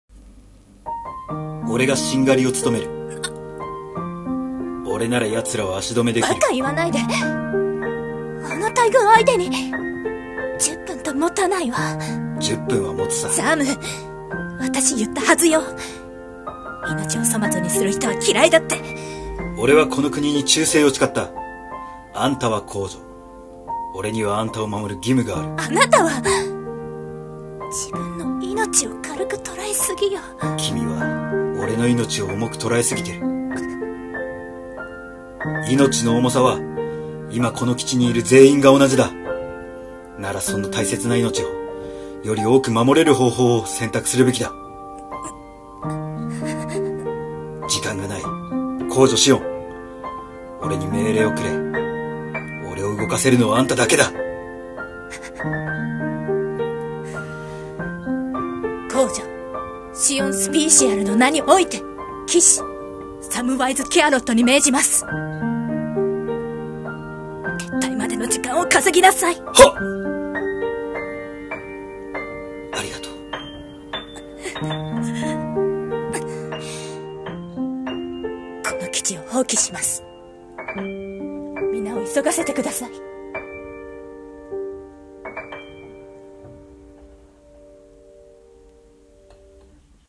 【二人声劇】シオンの騎士